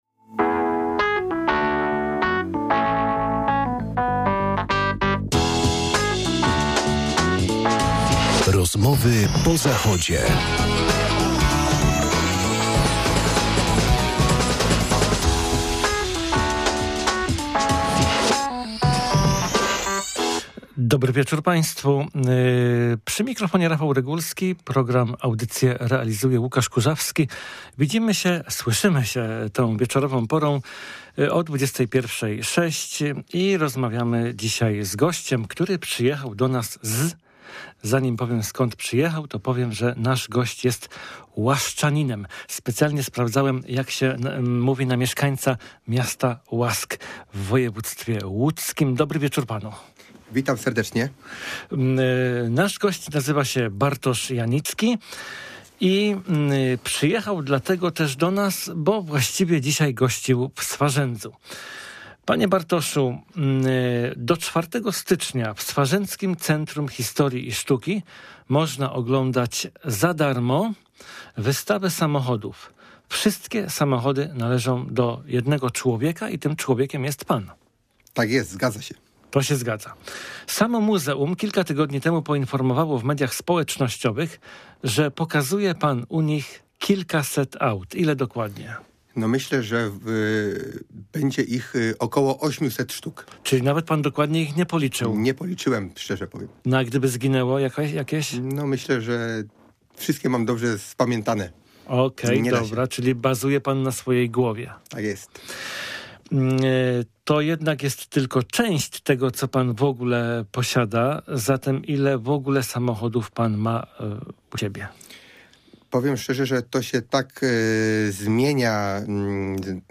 Udowadnia to chyba najbardziej wtedy, gdy z pytaniami do studia zadzwonił słuchacz...